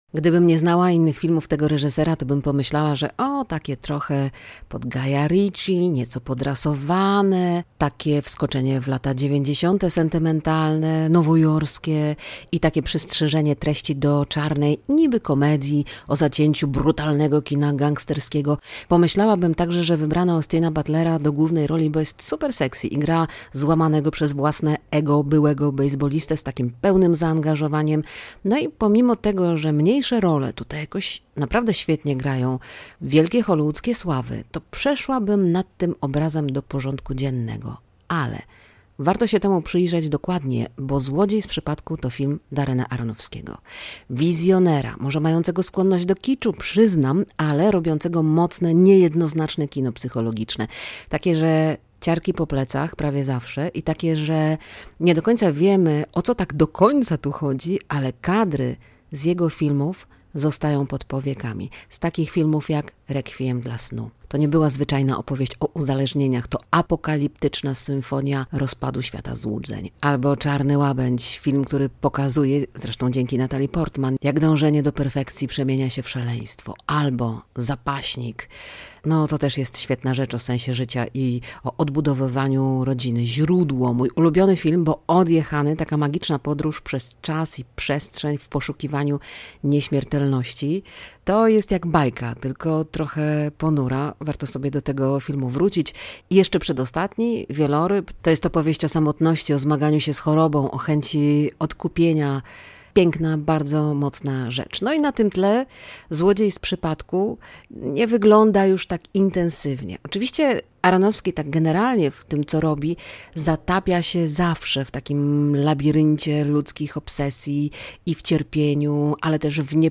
recenzja